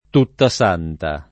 tuttasanta [ tutta S# nta ]